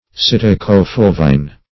Search Result for " psitta-co-fulvine" : The Collaborative International Dictionary of English v.0.48: Psitta-co-fulvine \Psit`ta-co-ful"*vine\, n. [Gr.